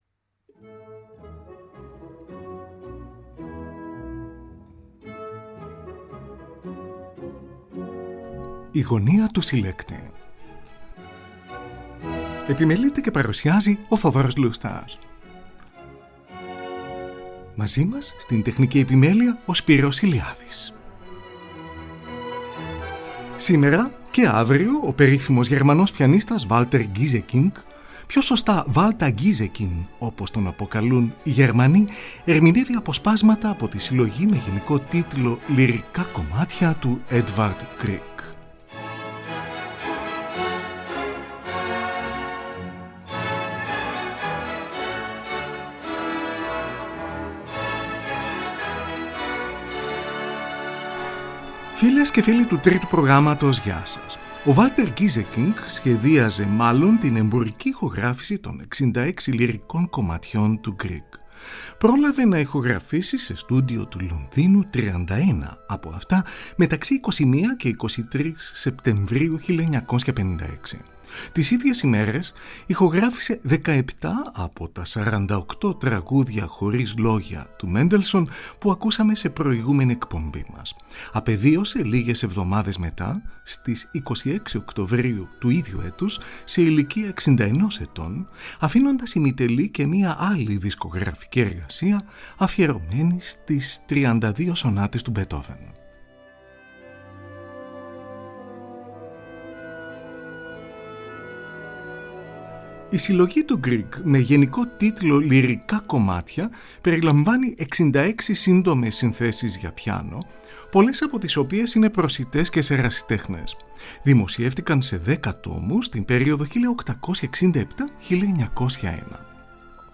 περιλαμβάνει 66 σύντομες συνθέσεις για πιάνο